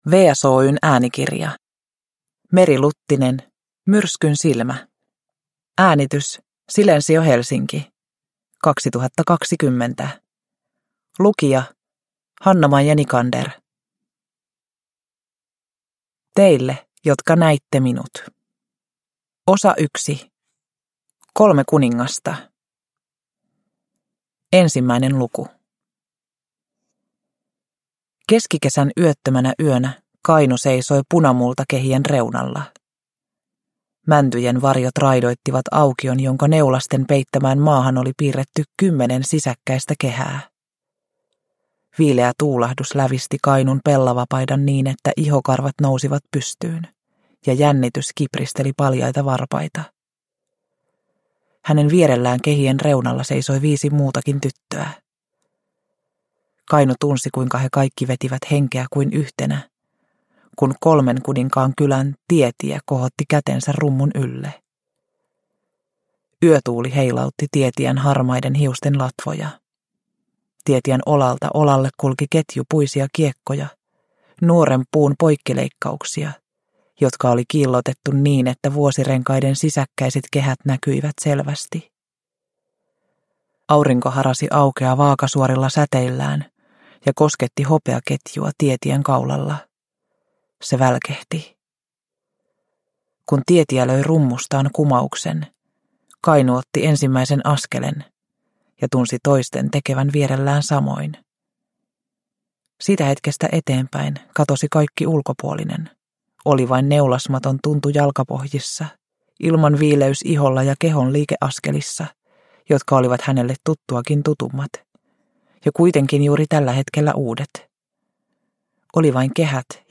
Myrskynsilmä – Ljudbok – Laddas ner